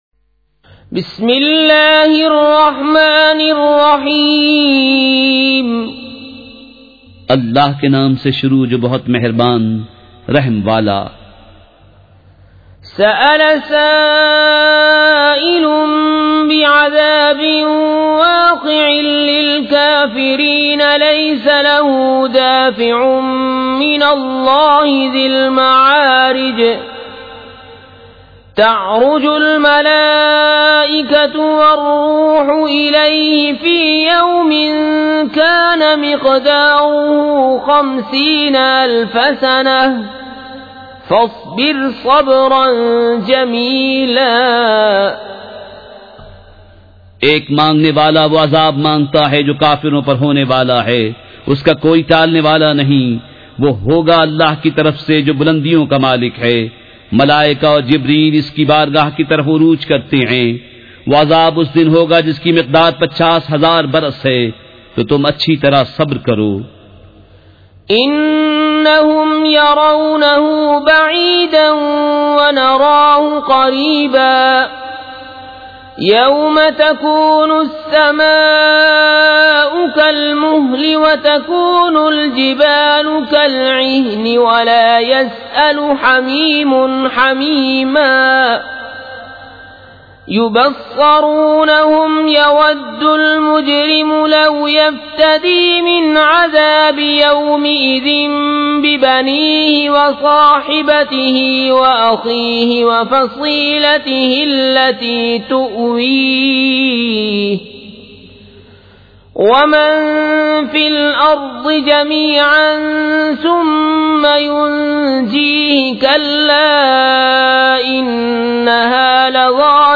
سورۃ المعارج مع ترجمہ کنزالایمان ZiaeTaiba Audio میڈیا کی معلومات نام سورۃ المعارج مع ترجمہ کنزالایمان موضوع تلاوت آواز دیگر زبان عربی کل نتائج 1966 قسم آڈیو ڈاؤن لوڈ MP 3 ڈاؤن لوڈ MP 4 متعلقہ تجویزوآراء